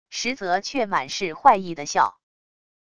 实则却满是坏意的笑wav音频